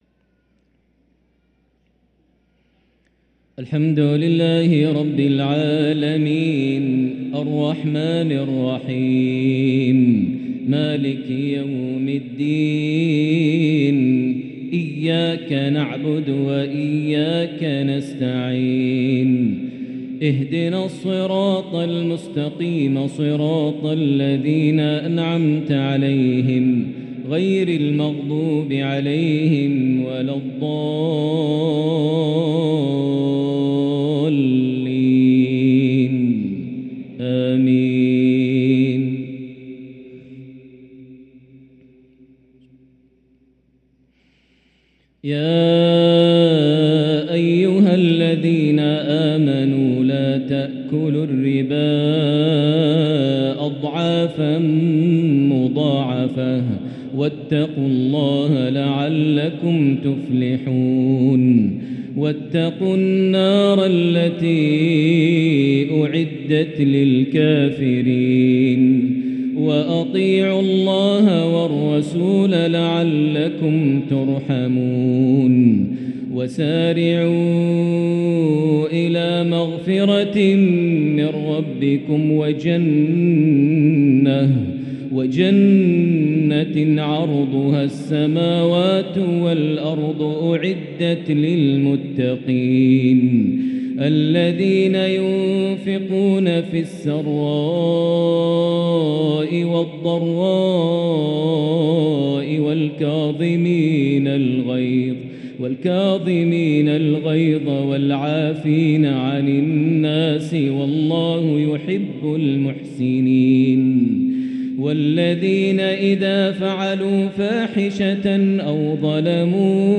تلاوة مؤثرة من سورة آل عمران (130-145) عشاء الخميس 3-8-1444هـ > 1444 هـ > الفروض - تلاوات ماهر المعيقلي